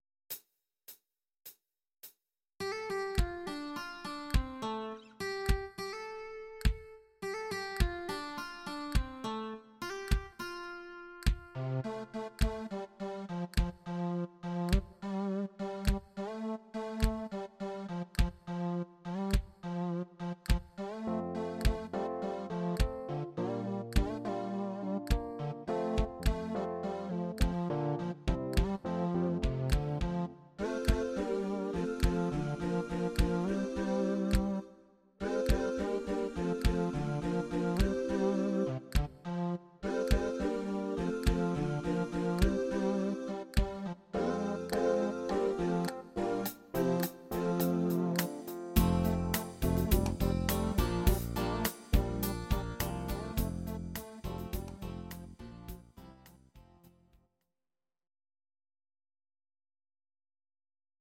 Audio Recordings based on Midi-files
Pop, Rock, Country, 2000s